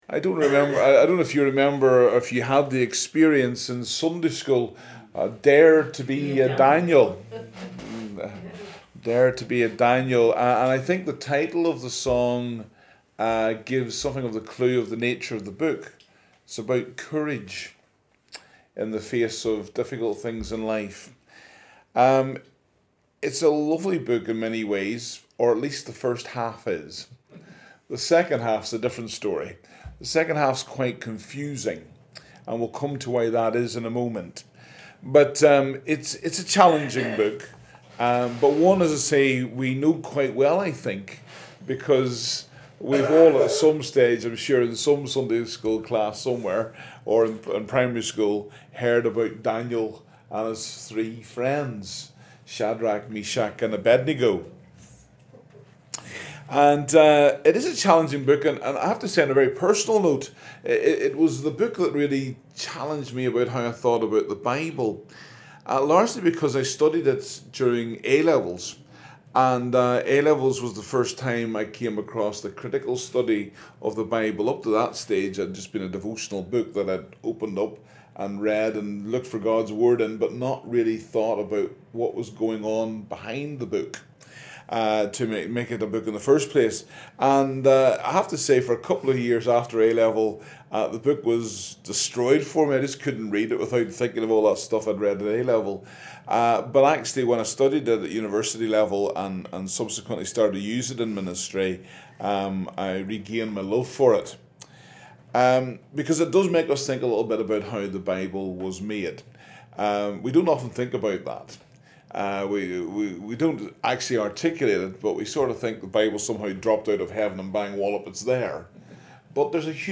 Download the live Session as an MP3 audio file